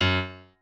piano4_47.ogg